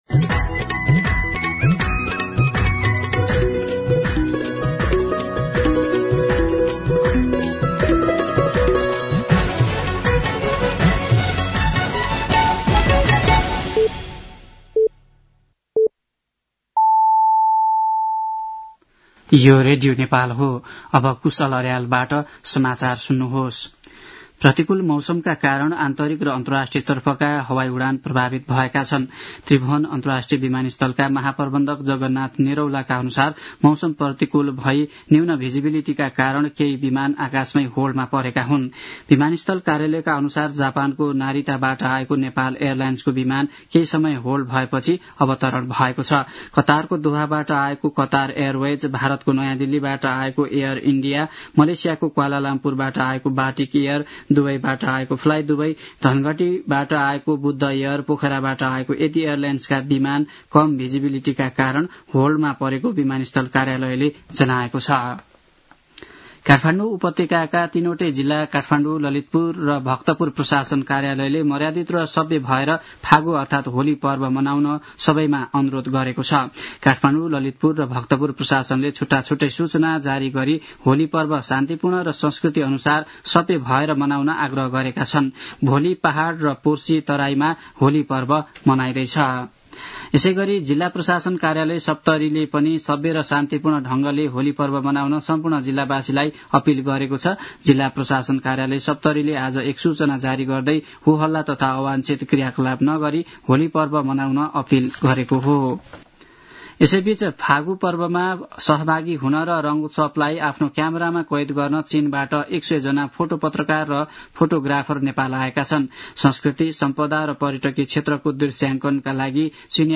साँझ ५ बजेको नेपाली समाचार : २९ फागुन , २०८१